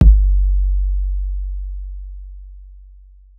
SouthSide Kick Edited (4).wav